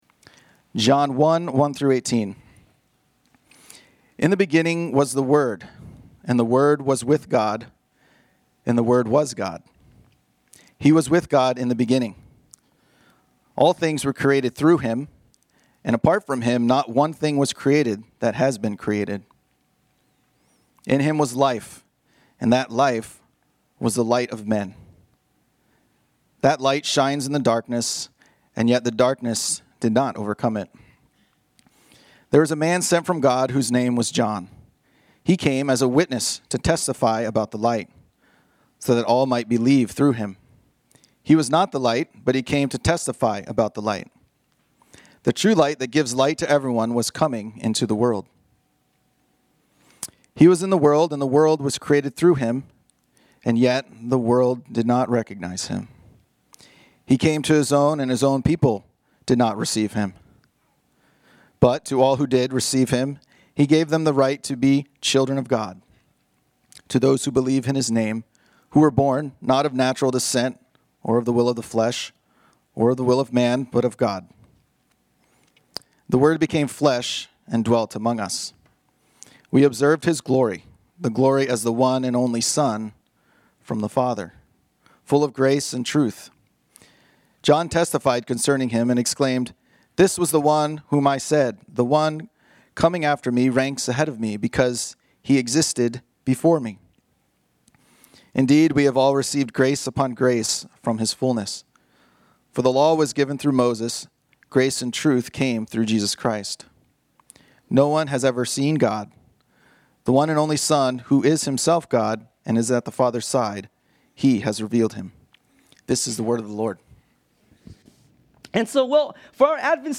This sermon was originally preached on Sunday, December 28, 2025.